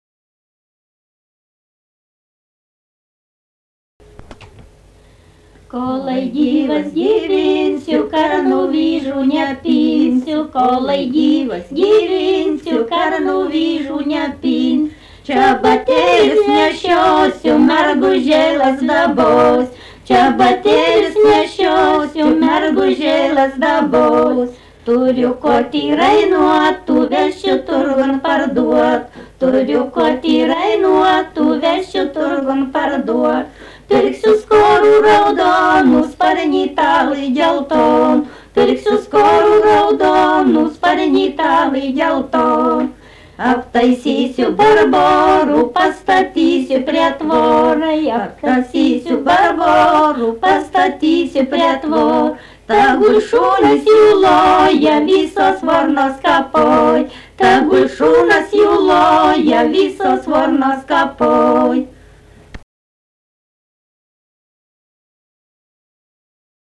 daina
Šimonys
vokalinis